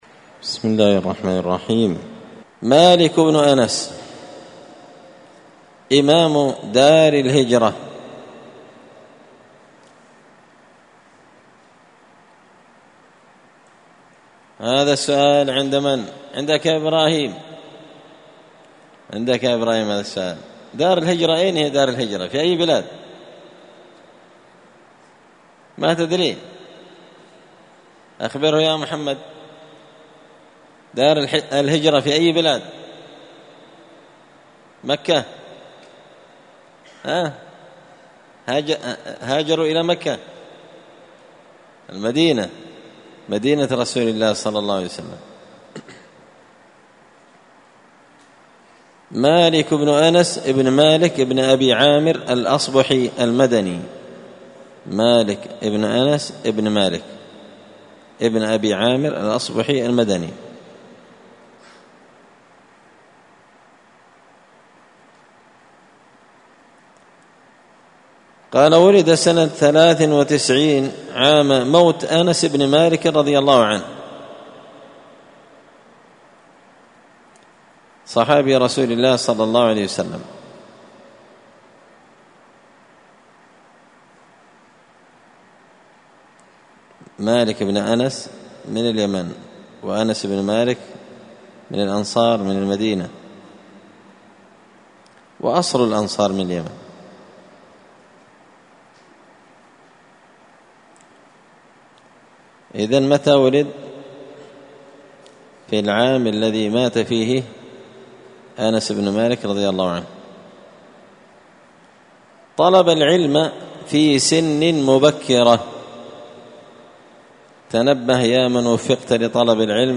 السبت 20 ذو الحجة 1444 هــــ | الدروس | شارك بتعليقك | 12 المشاهدات